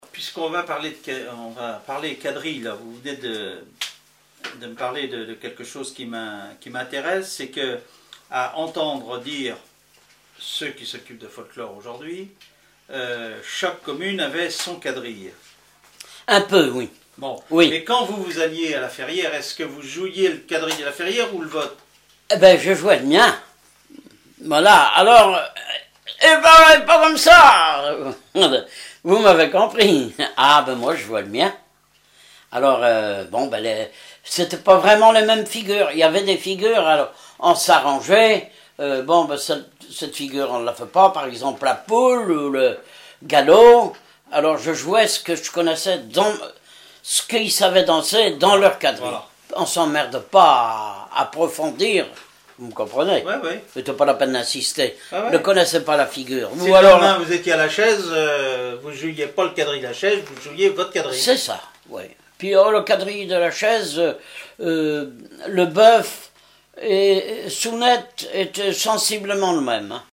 Enquête Compagnons d'EthnoDoc - Arexcpo en Vendée
Catégorie Témoignage